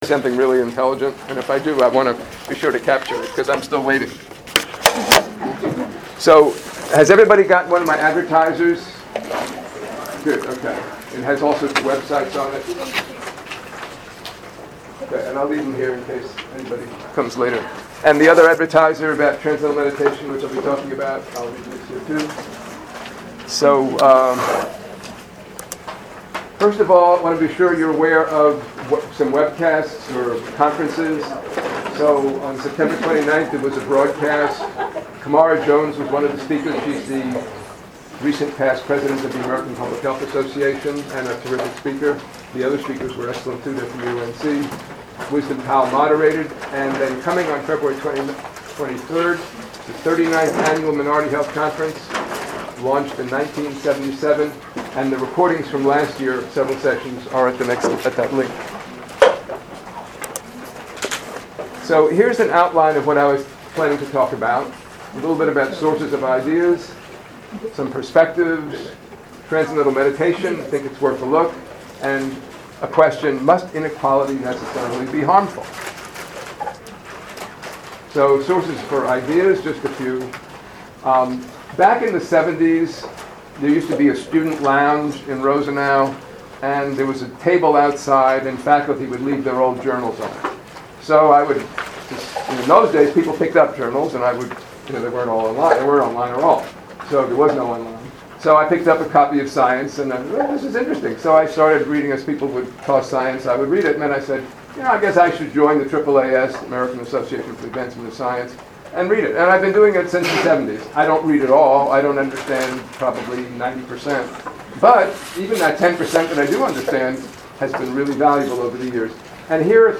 Presentation at Global Health "Lunch and Learn" at Gillings School of Global Public Health, November 14, 2017, noon-1pm.